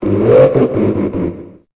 Cri de Gouroutan dans Pokémon Épée et Bouclier.